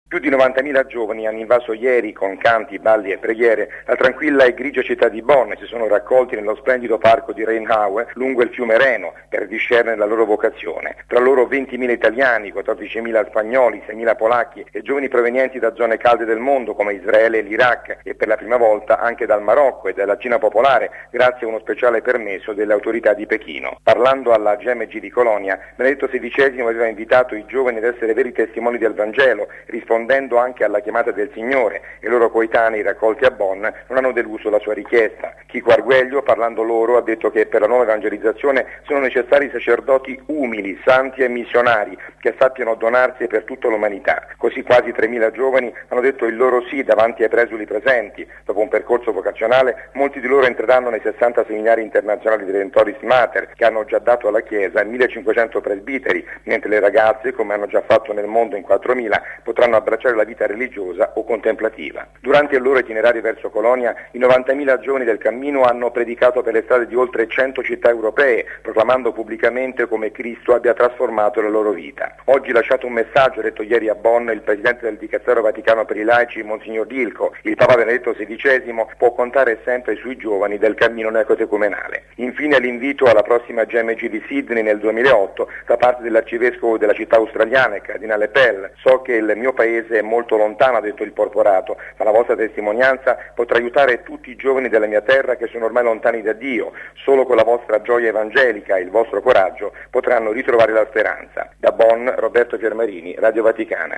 Da Bonn